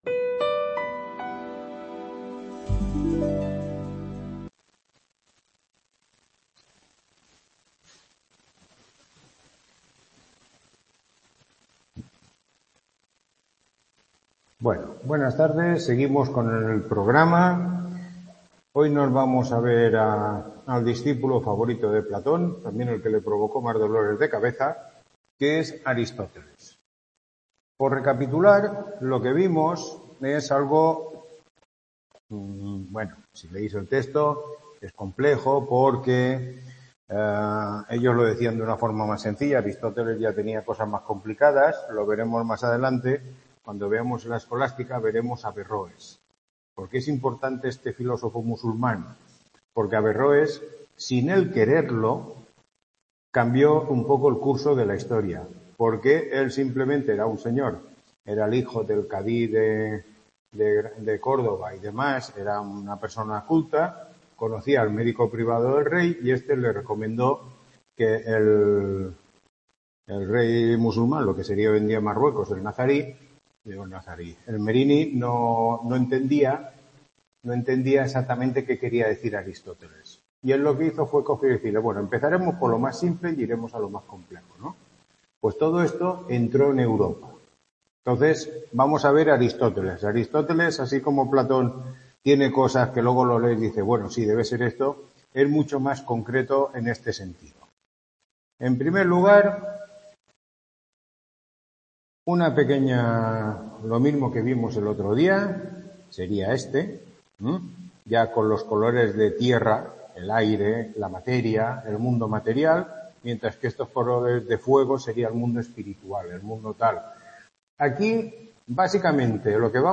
Tutoría 5